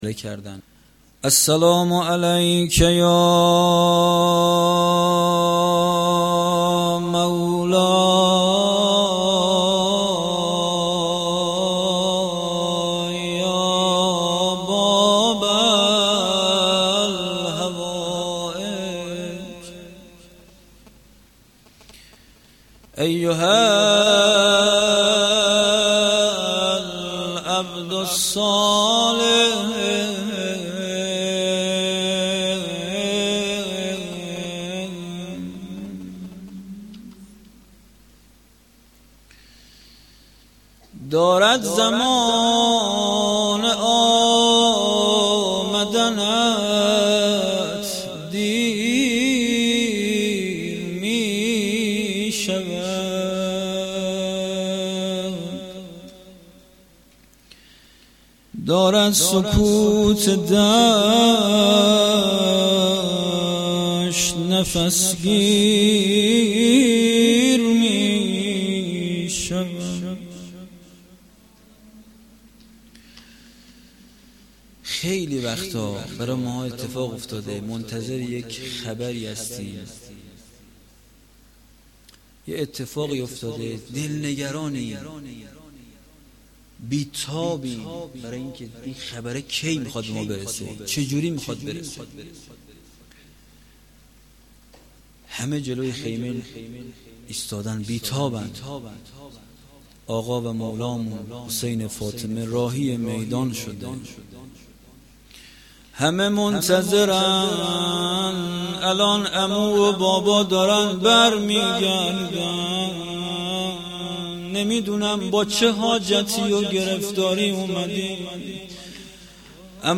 گزارش صوتی جلسه هفتگی2دیماه
روضه